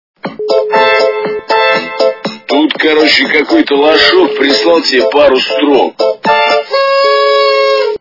» Звуки » звуки для СМС » Мужской Голос - Тут, короче, какой-то лошок прислал тебе пару строк
При прослушивании Мужской Голос - Тут, короче, какой-то лошок прислал тебе пару строк качество понижено и присутствуют гудки.